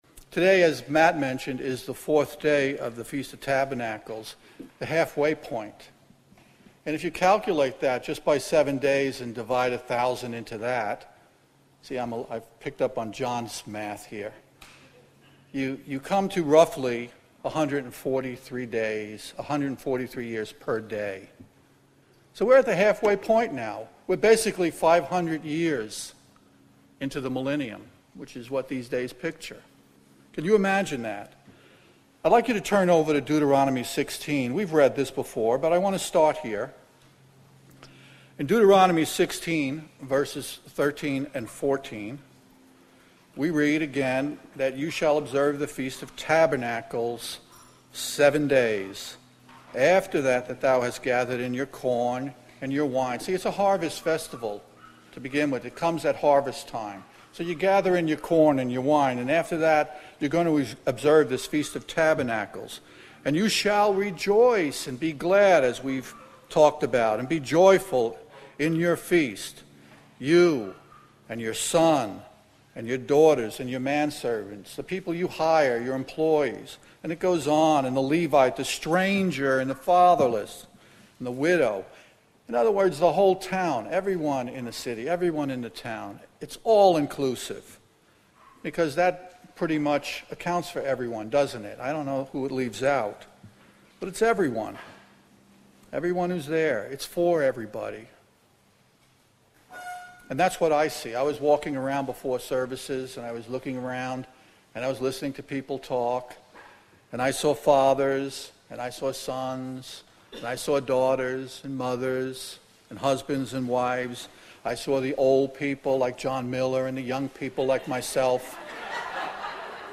Given in North Canton, OH Sugarcreek, OH